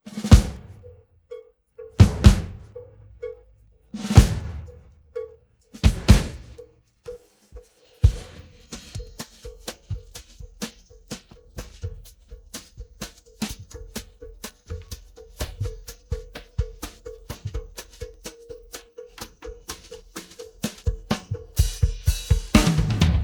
tmpd6_mx2pjday-of-sun-clip_drums.wav